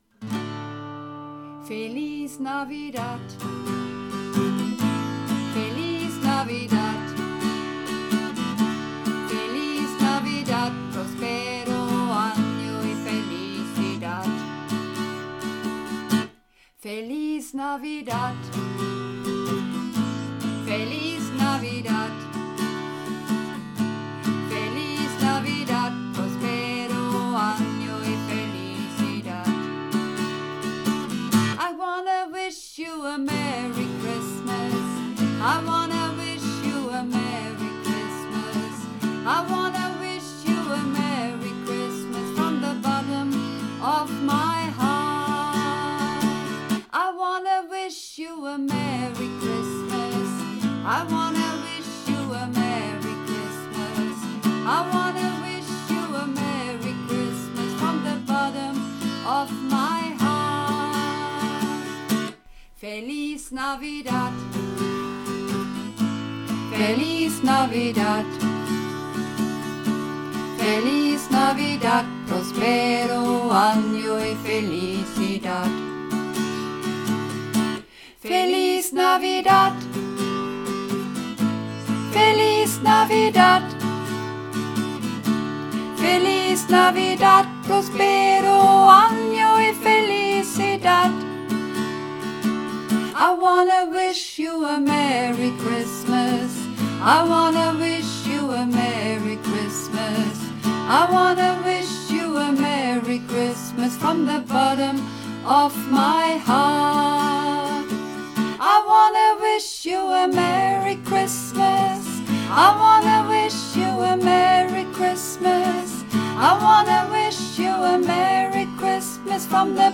Übungsaufnahmen - Feliz Navidad
Feliz Navidad (Sopran)
Feliz_Navidad__3_Sopran.mp3